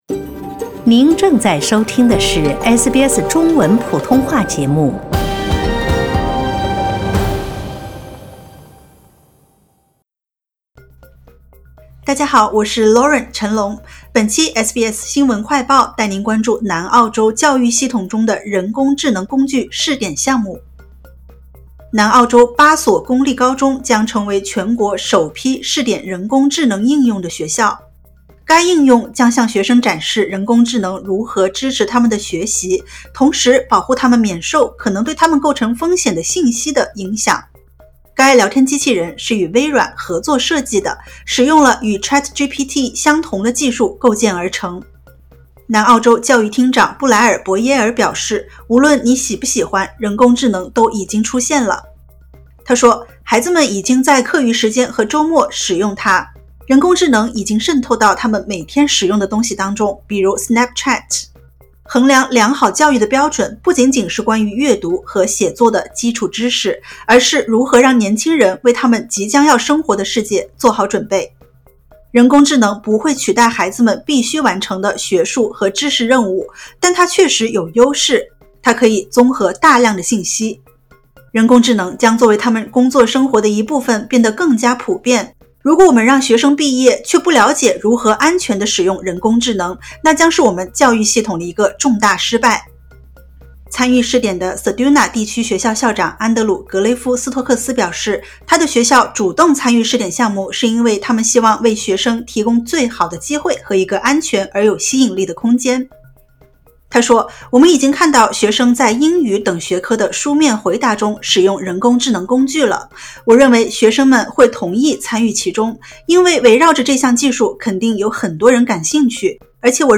【SBS新闻快报】ChatGPT式AI应用将在南澳八所高中展开试点活动